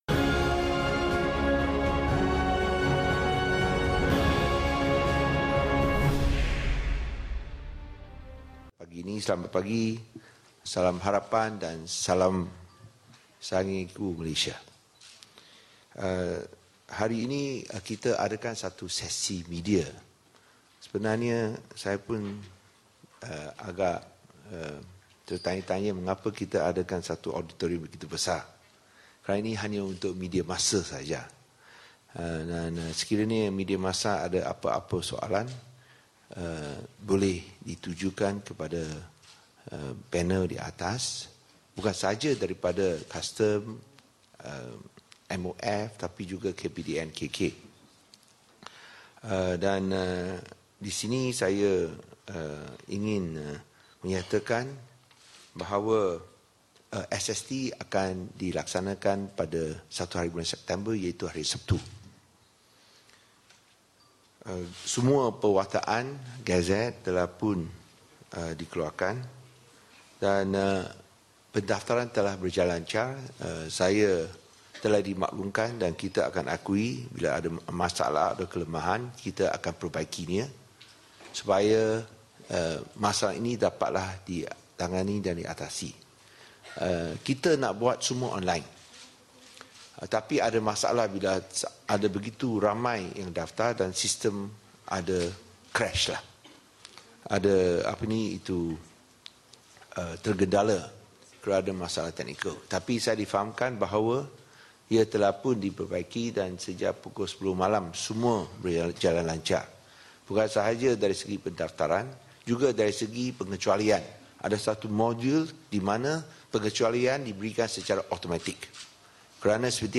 Taklimat Cukai Jualan dan Perkhidmatan SST oleh Menteri Kewangan Lim Guan Eng di Putrajaya.